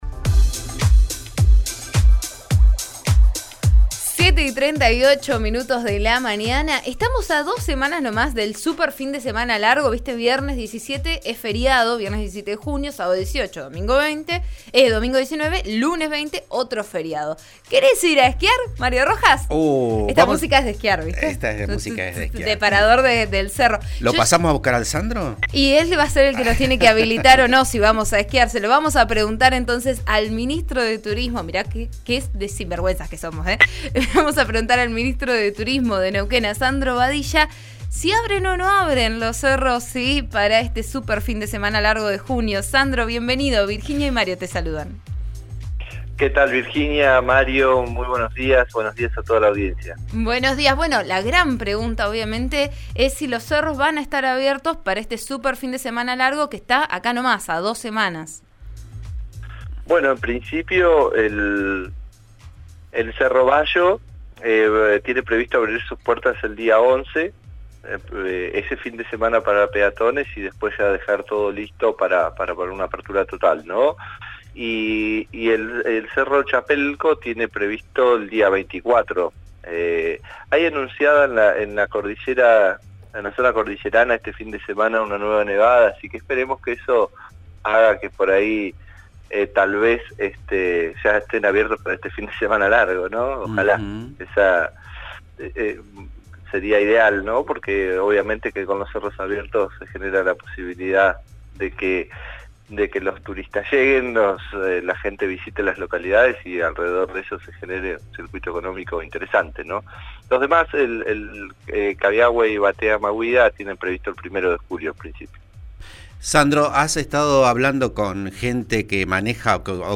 En diálogo con Vos A Diario por RN Radio, el ministro de Turismo, Sandro Badilla aseguró que la temporada va a ser muy buena.